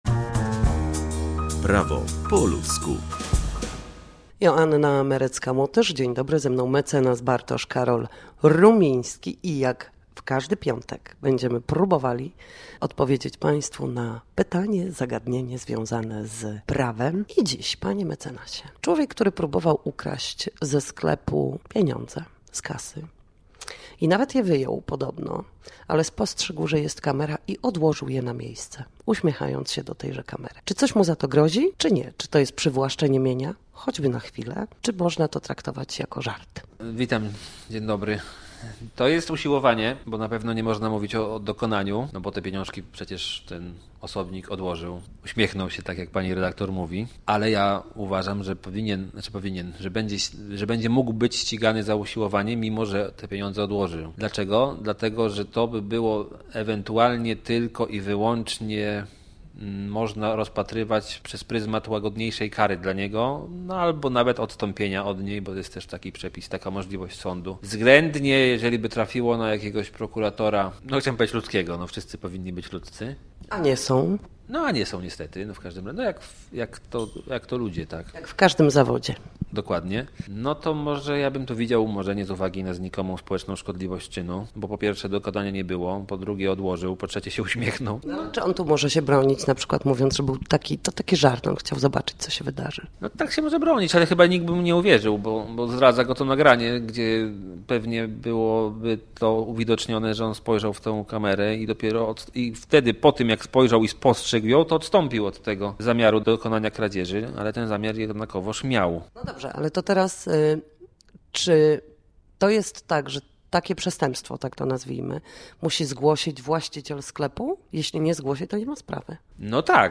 Nasi goście, prawnicy, odpowiadać będą na jedno pytanie dotyczące zachowania w sądzie i podstawowych zagadnień prawniczych.